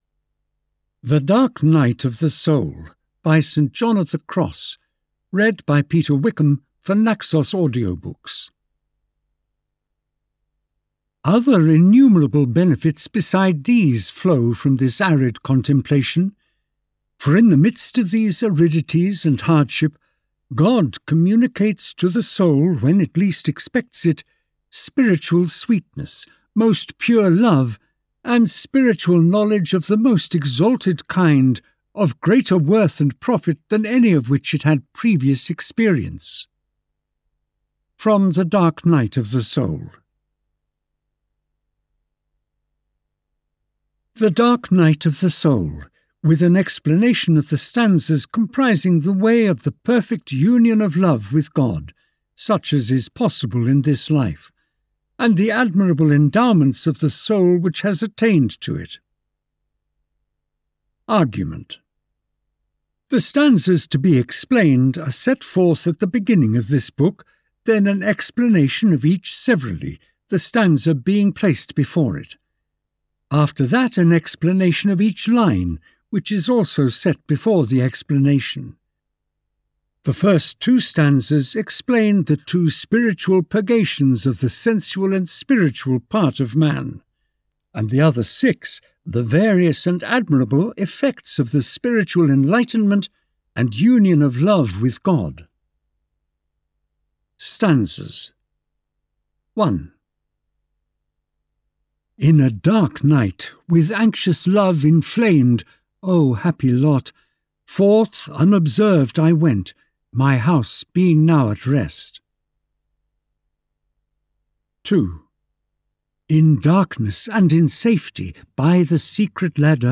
The Dark Night of the Soul (EN) audiokniha
Ukázka z knihy